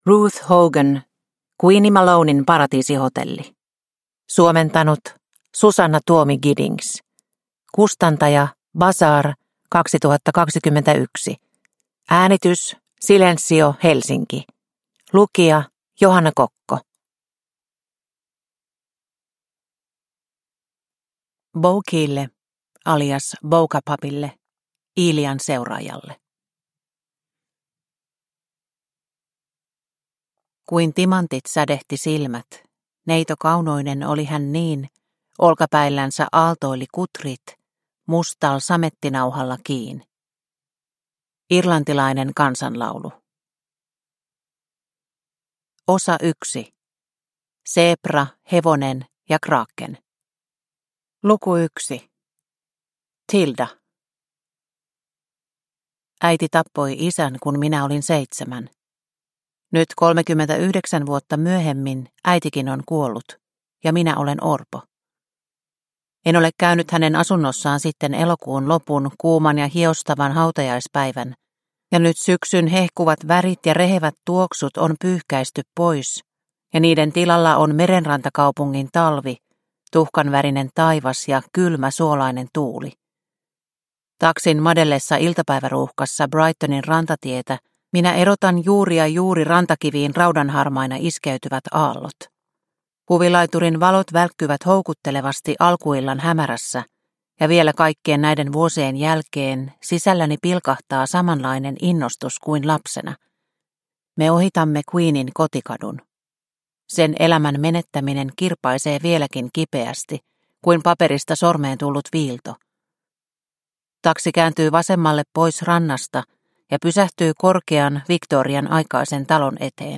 Queenie Malonen Paratiisihotelli – Ljudbok – Laddas ner